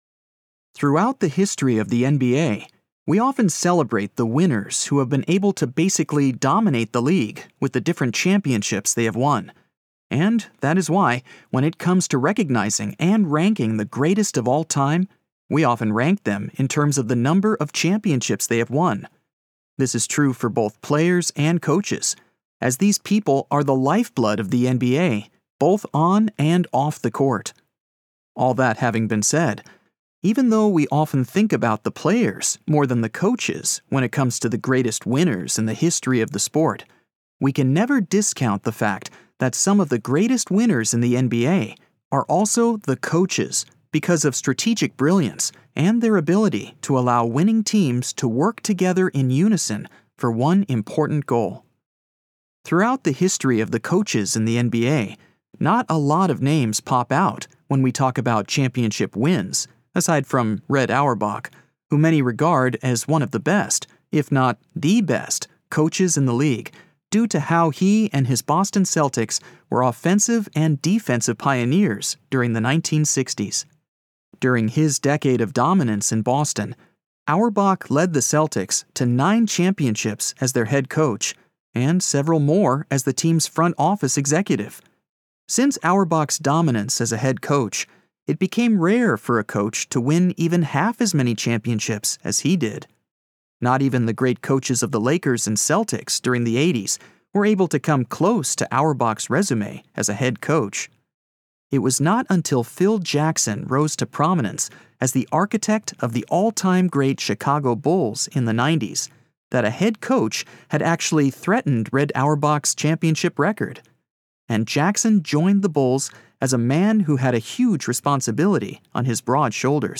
Audiobook Demo – Sports Biography